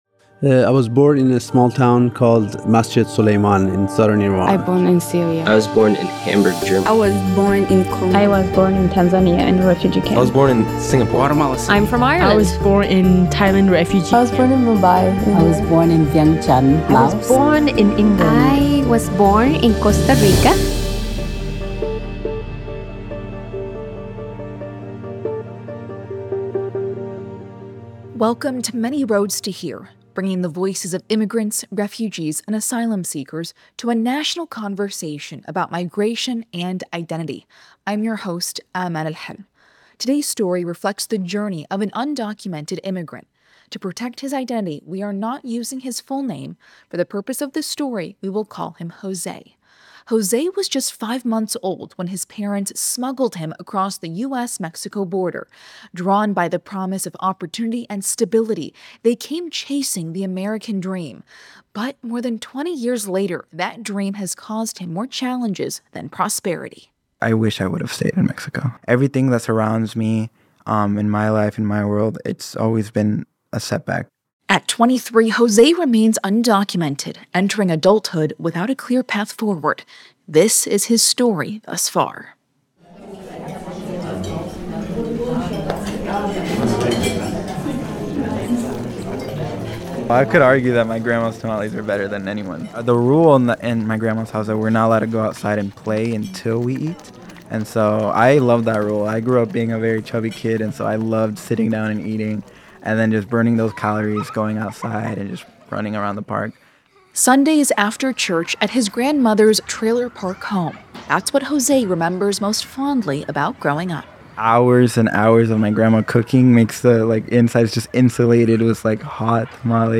Thank you to St. Andrew Lutheran Church in Beaverton, Oregon, for letting us use their space to record this interview.